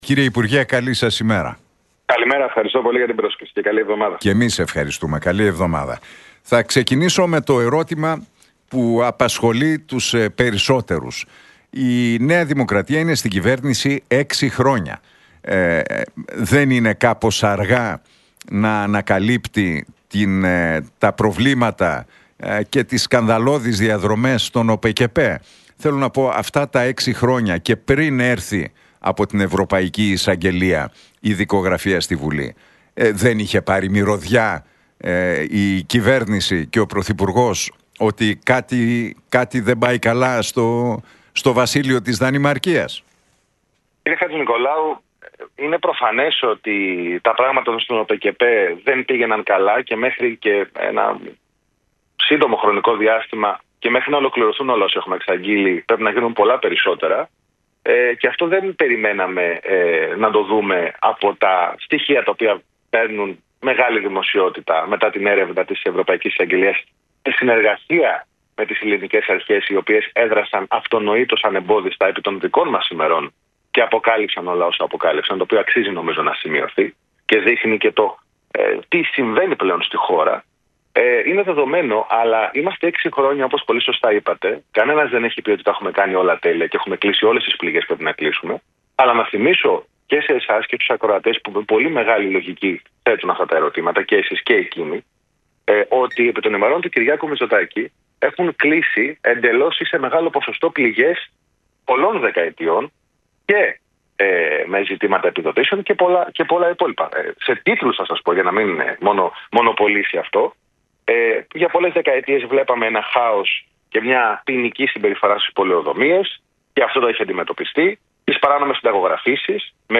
Για το σκάνδαλο με τις παράνομες επιδοτήσεις στον ΟΠΕΚΕΠΕ, τη σχετική δικογραφία στη Βουλή και τις 4 παραιτήσεις που ζήτησε ο Κυριάκος Μητσοτάκης μίλησε ο υφυπουργός παρά τω Πρωθυπουργώ και κυβερνητικός εκπρόσωπος Παύλος Μαρινάκης στον Νίκο Χατζηνικολάου από την συχνότητα του Realfm 97,8.